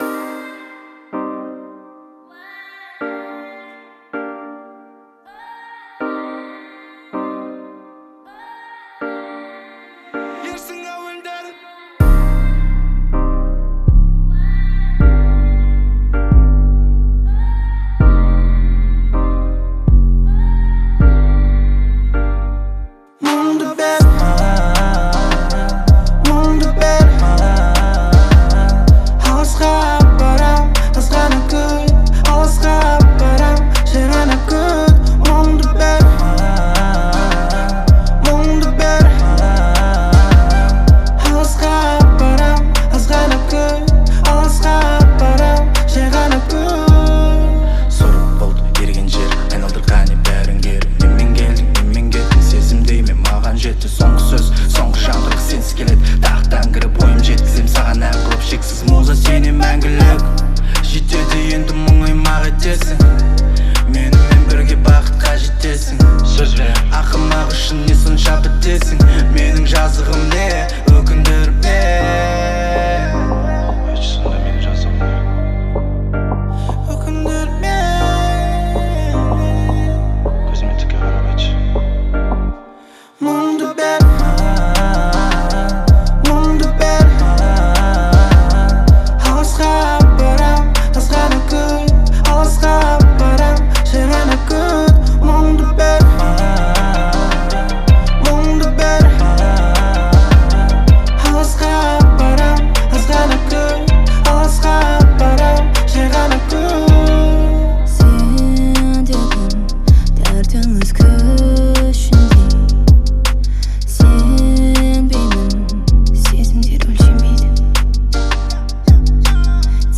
это завораживающий трек в жанре поп с элементами фолка